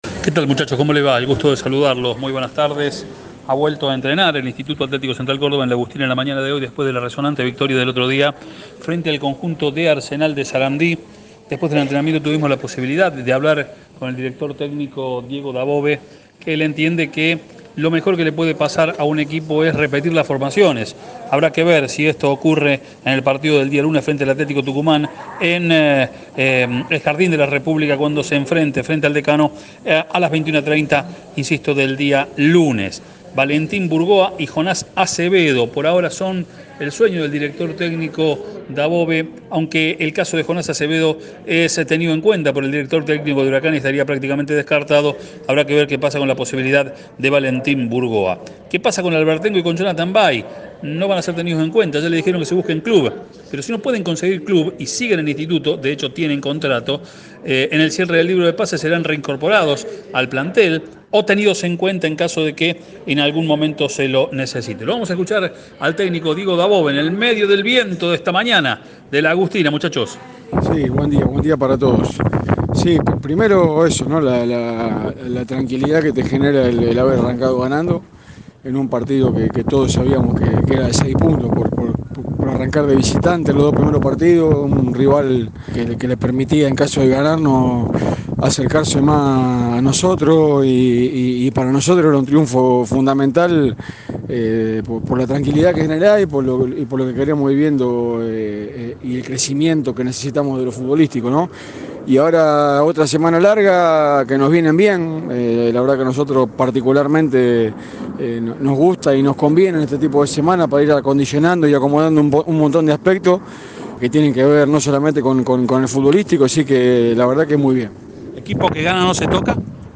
Habló con Cadena 3 de cómo vive el presente de "La Gloria".